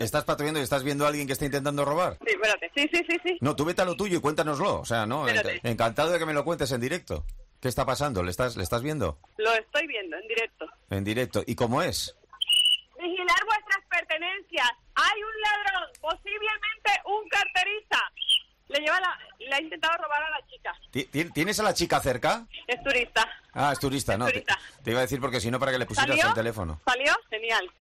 La magia de la radio en directo.